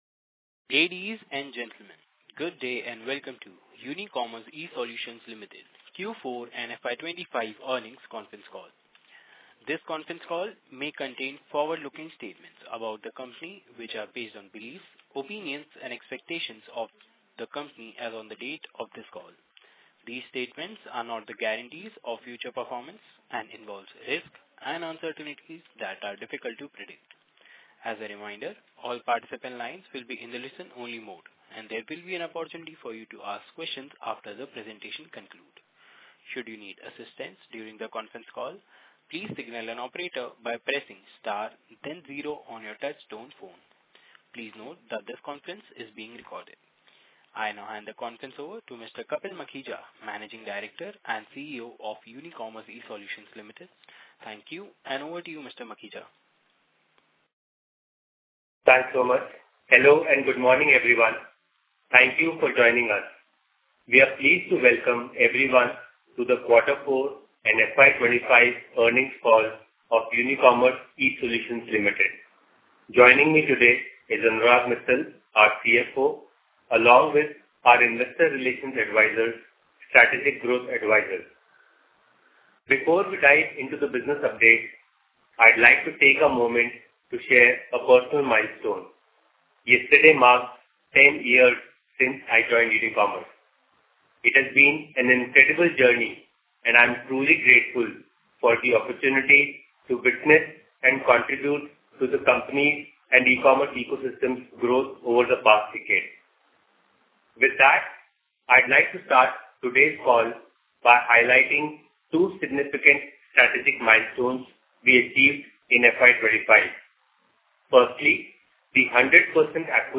Concalls
Unicommerce-Earnings-Call-Audio-Q4FY25.mp3